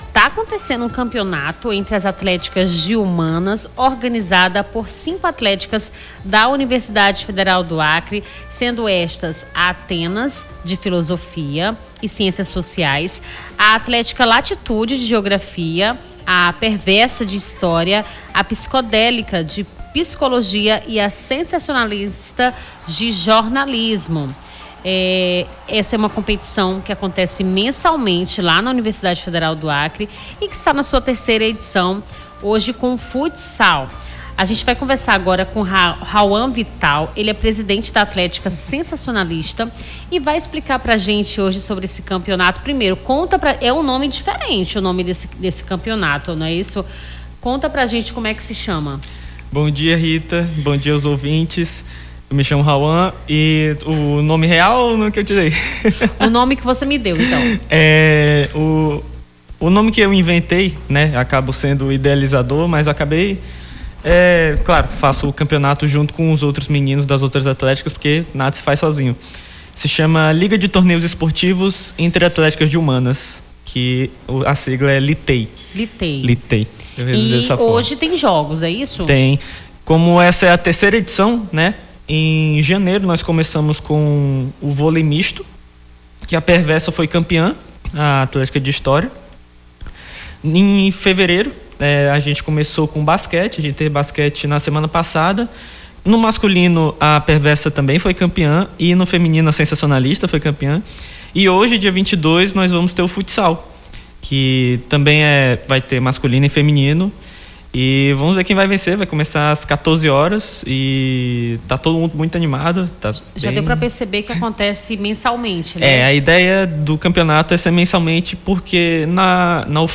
Nome do Artista - CENSURA - ENTREVISTA CAMPEONATO ATLÉTICAS (22-02-25).mp3